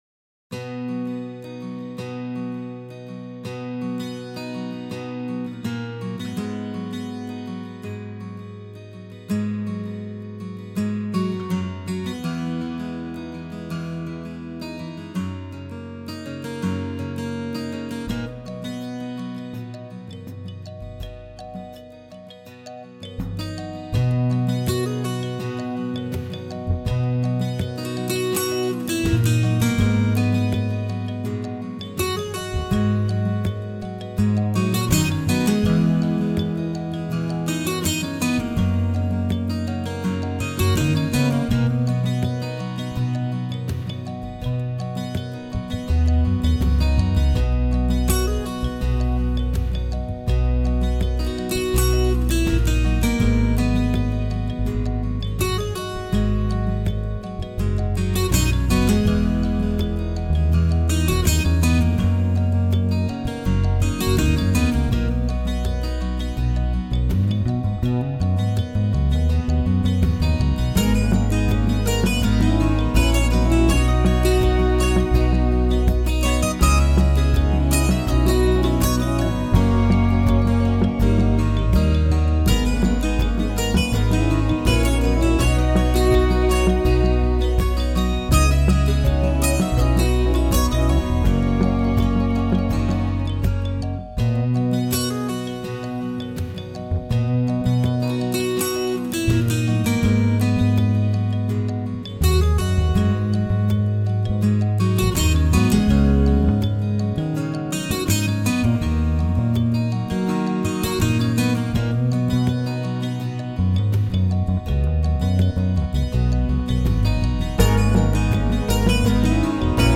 Acoustic 12-string guitar for Kontakt